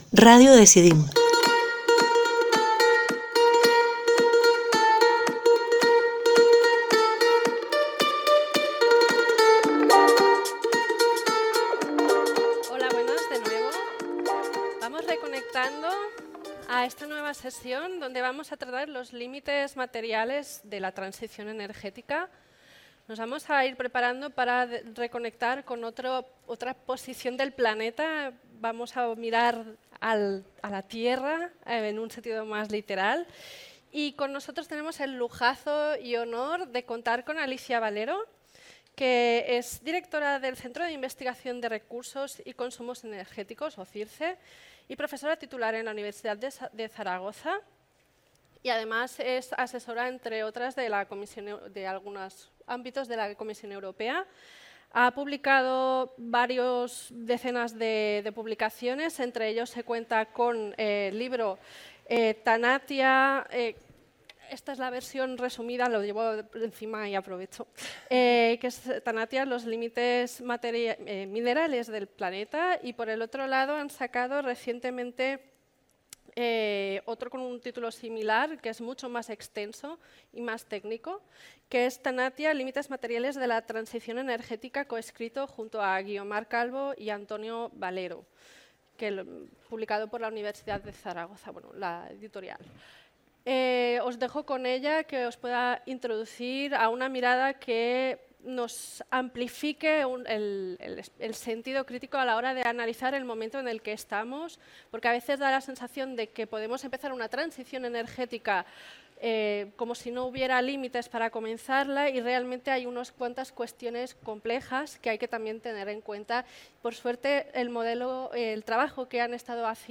En conversación